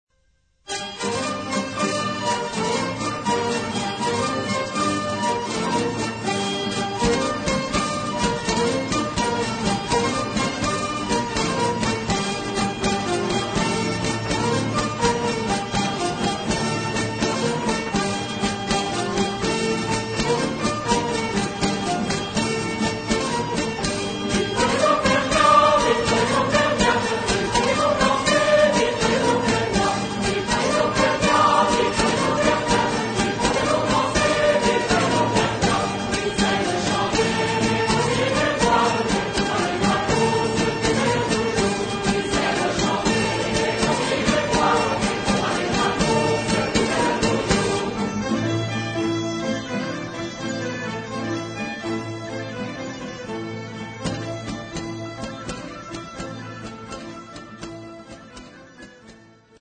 Genre-Style-Form: Secular ; Popular ; Bourrée
Mood of the piece: dancing
Type of Choir: SATB  (4 mixed voices )
Tonality: G minor